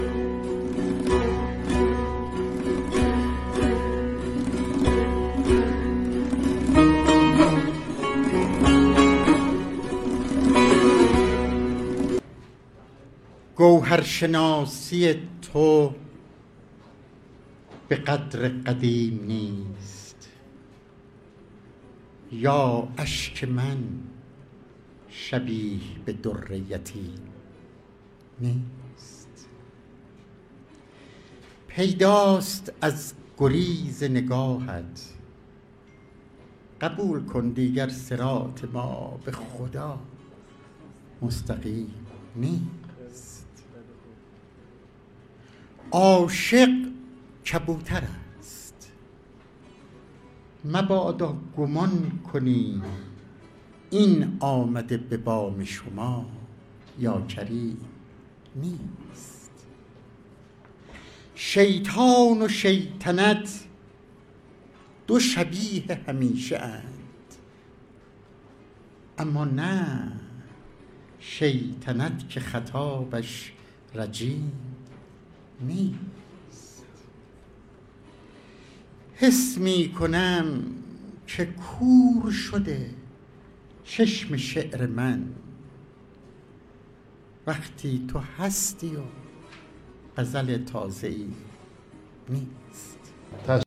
دانلود دکلمه ما ساکن دلیم با صدای محمدعلی بهمنی
گوینده :   [محمدعلی بهمنی]